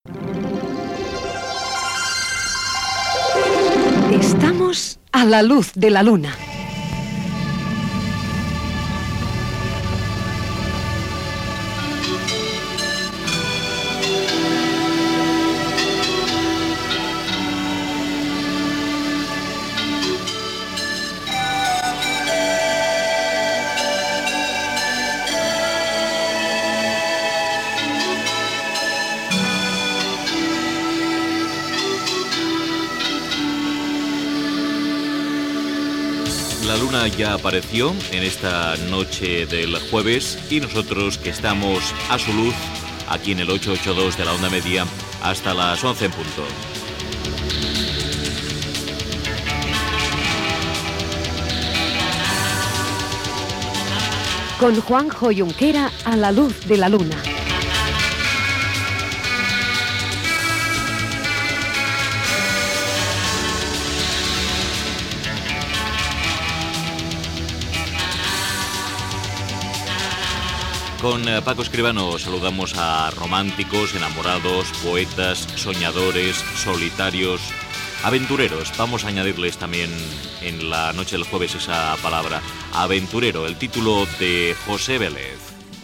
Identificació del programa, presentació
Entreteniment